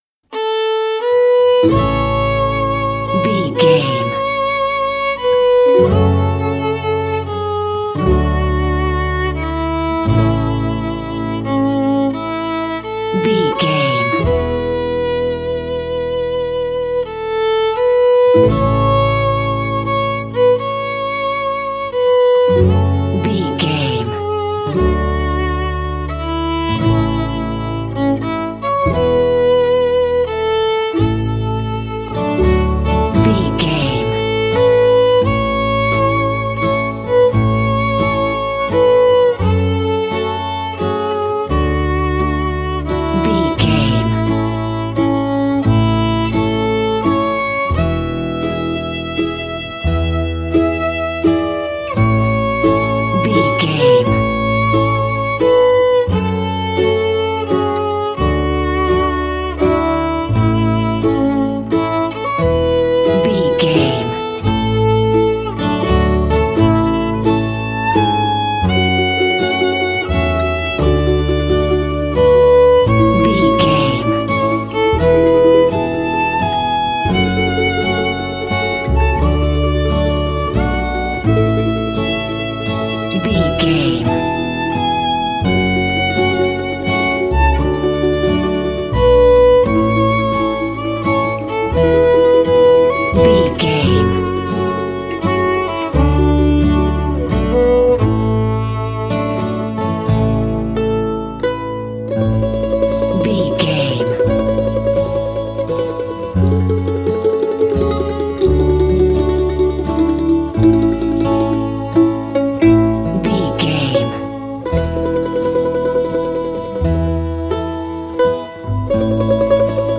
Ionian/Major
Slow
acoustic guitar
bass guitar
violin
banjo